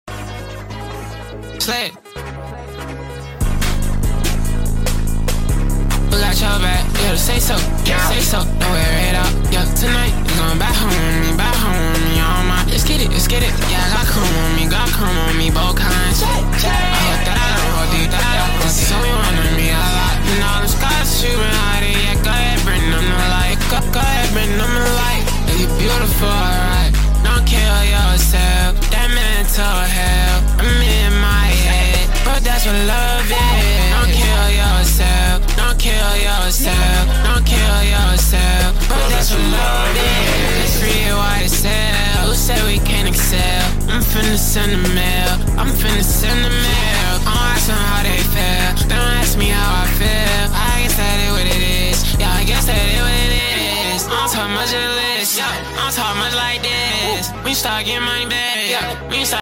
type beat